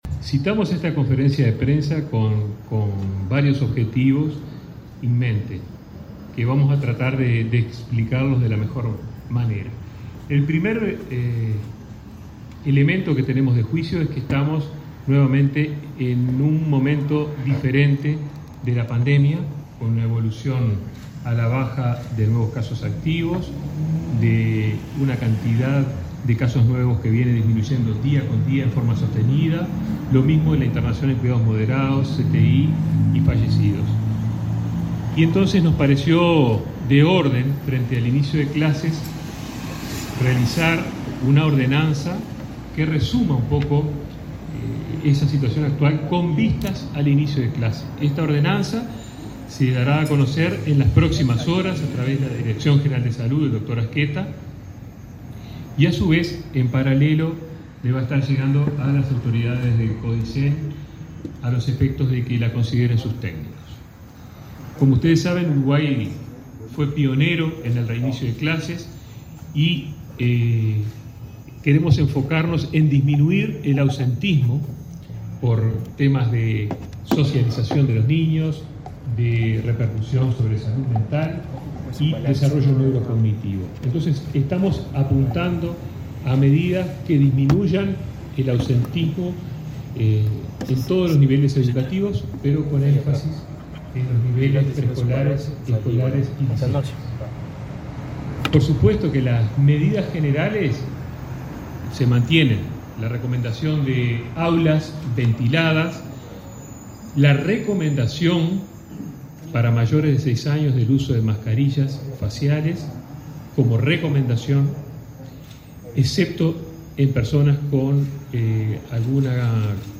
Palabras del ministro de Salud Pública, Daniel Salinas
El titular de Salud Pública, Daniel Salinas, brindó este miércoles 23 una conferencia de prensa para informar sobre cambios en el protocolo de COVID